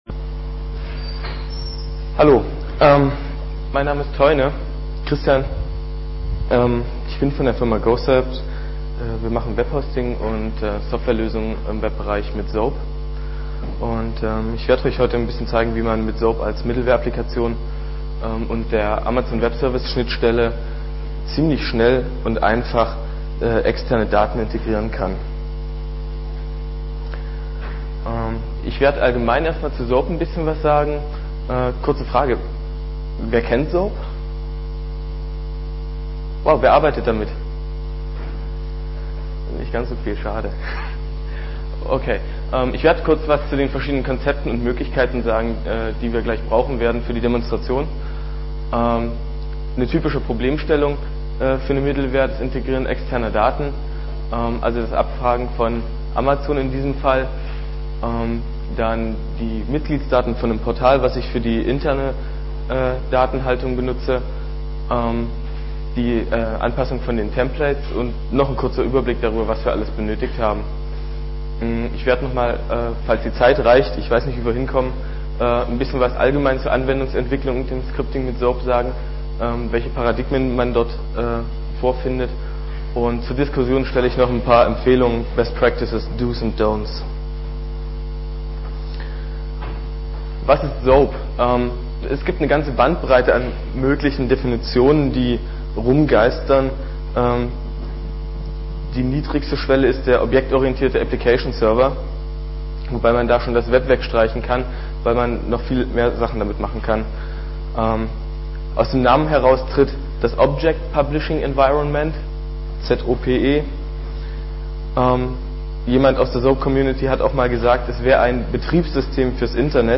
5. Chemnitzer Linux-Tag
Vortragsmittschnitt